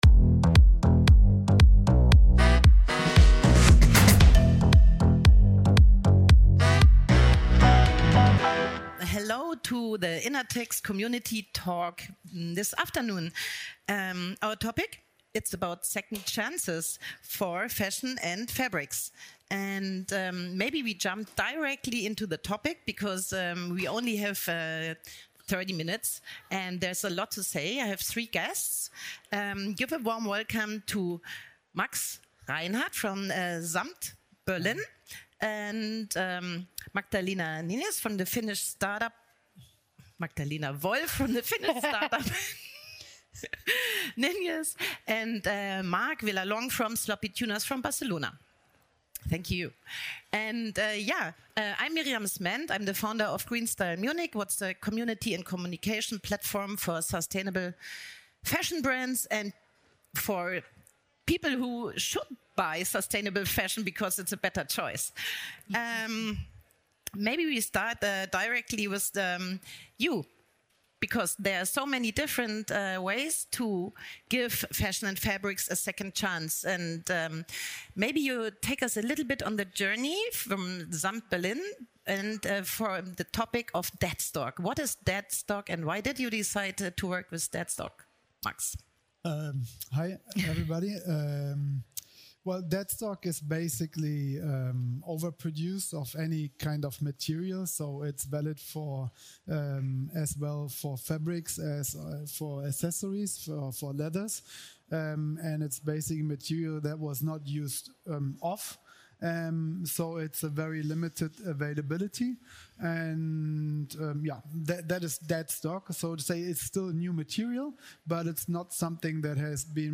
Expert-Talk (English) This panel discussion explores how fashion can endure beyond returns, surpluses, and forgotten materials. Three labels discuss their different approaches to greater responsibility in resource use and new ideas in the circular economy.